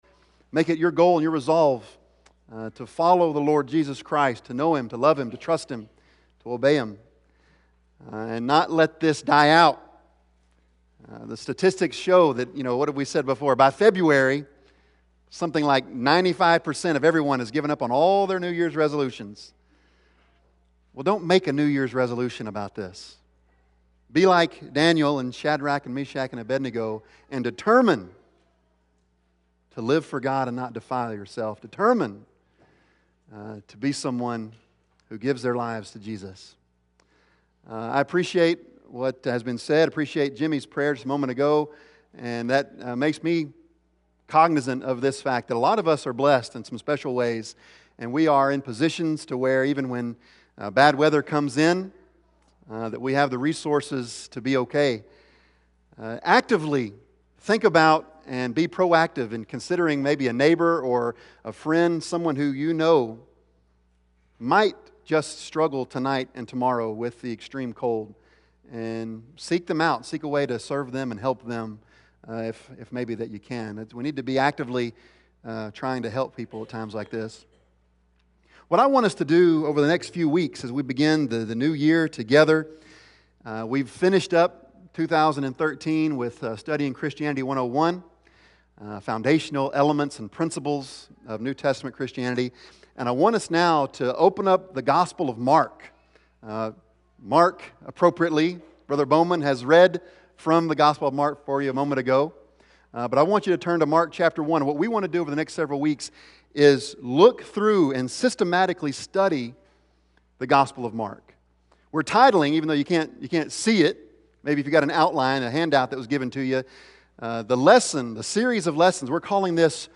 Bible Text: Mark 1:1-11 | Preacher